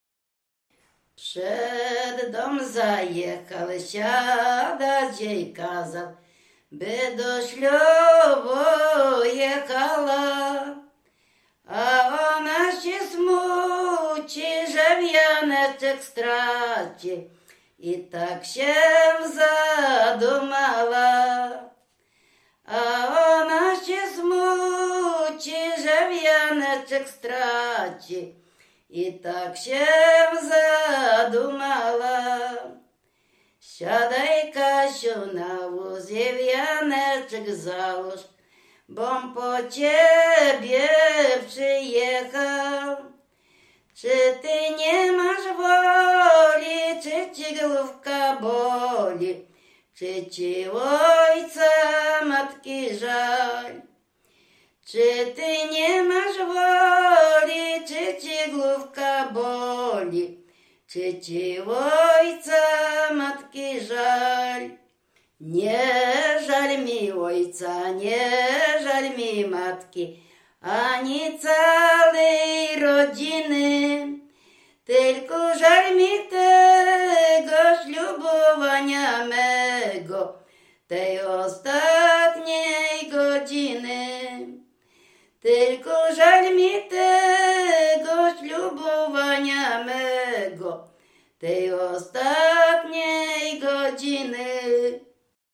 województwo dolnośląskie, powiat lwówecki, gmina Mirsk, wieś Mroczkowice
W wymowie Ł wymawiane jako przedniojęzykowo-zębowe;
e (é) w końcu wyrazu zachowało jego dawną realizację jako i(y)
Weselna
weselne wesele na wyjazd do kościoła